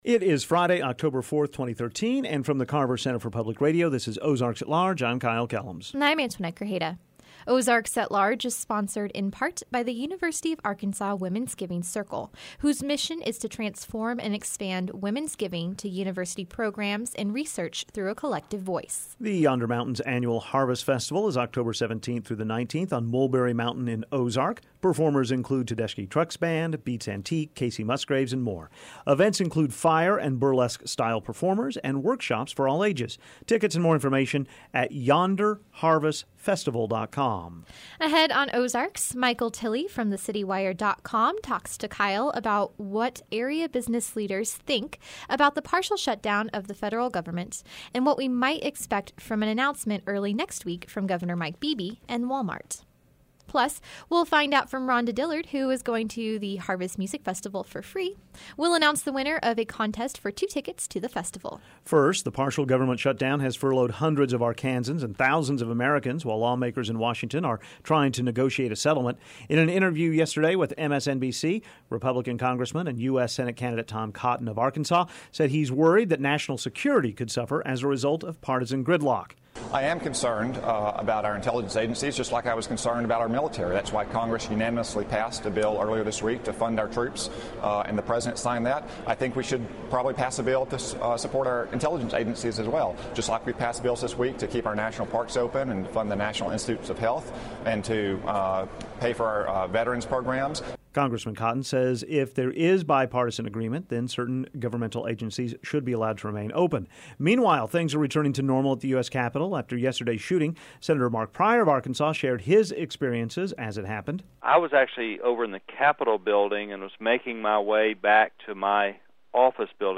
Plus, Block St. Hot Club joins us for a performance in the Firmin-Garner Performance Studio.